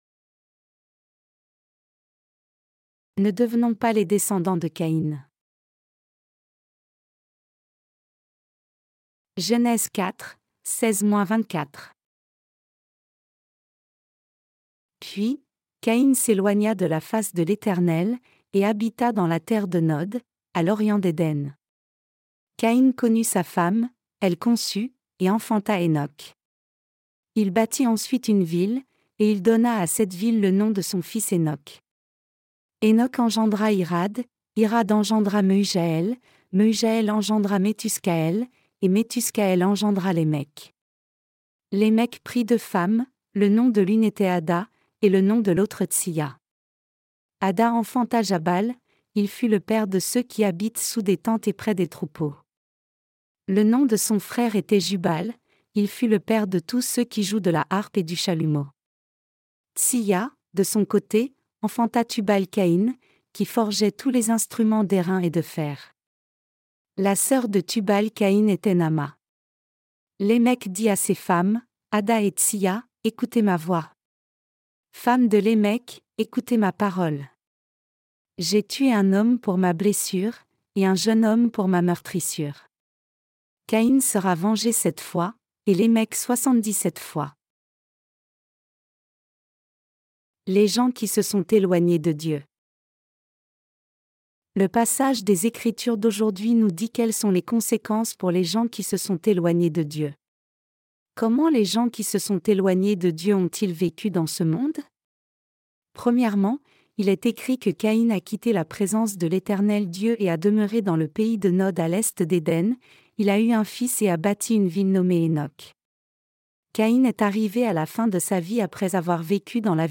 Sermons sur la Genèse (V) - LA DIFFERENCE ENTRE LA FOI D’ABEL ET LA FOI DE CAÏN 6.